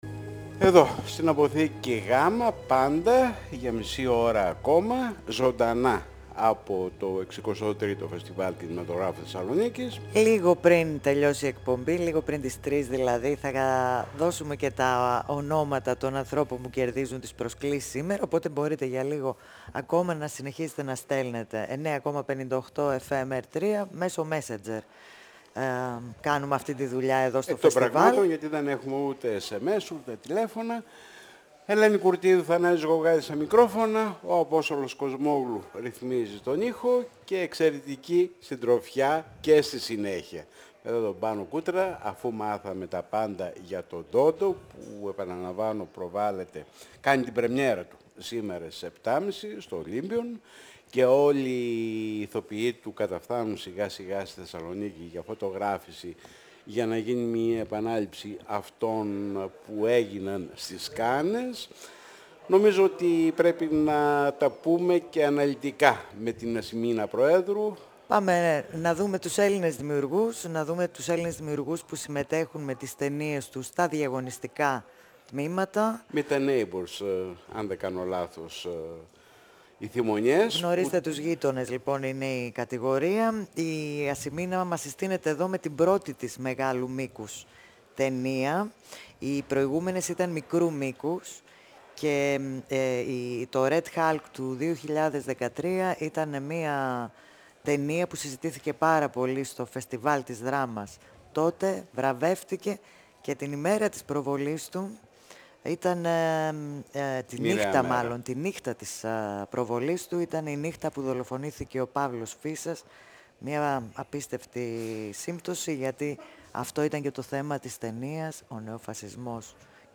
958FM Συνεντεύξεις